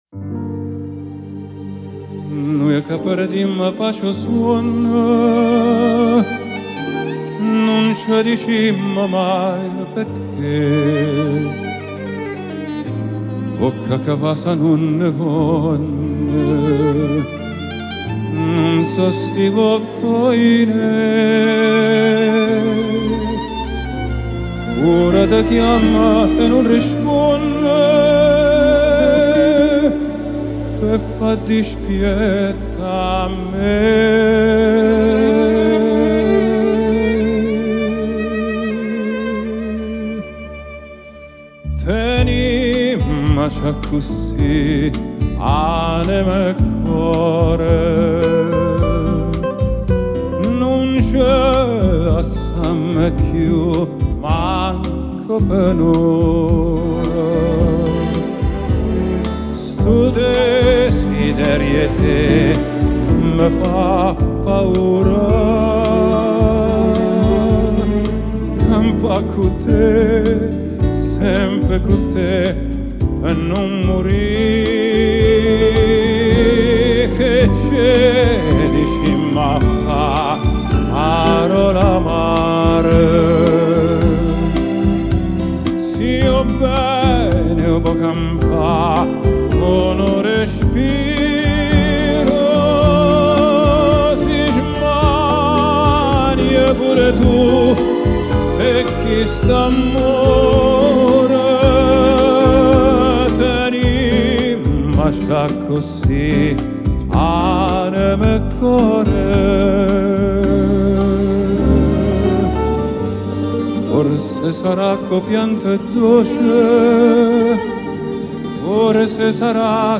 Genre: Vocal, Classical, Crossover